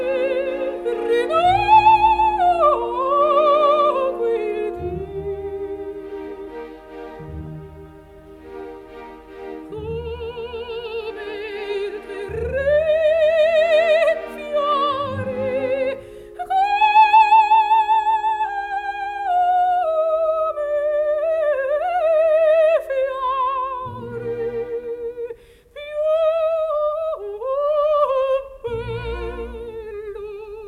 0 => "Musique classique"
0 => "Musique vocale, profane"